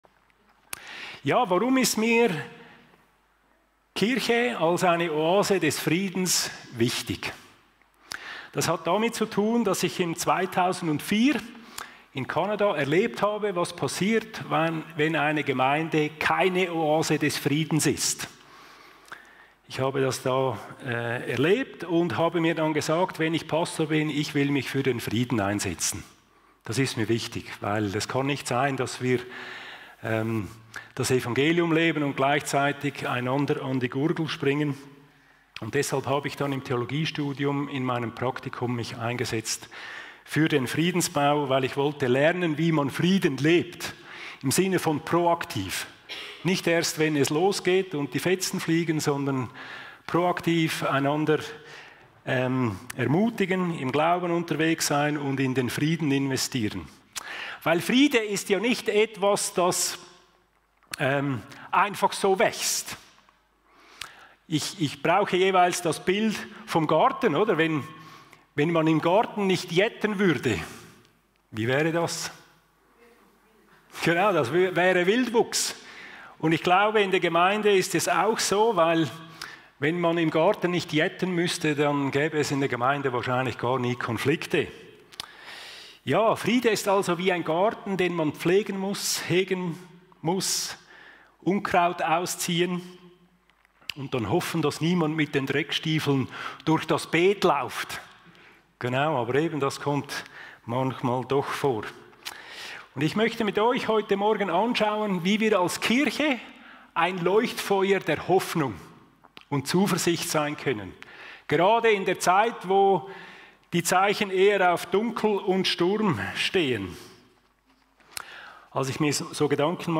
Gottesdienst-vom-2.-November-2025.mp3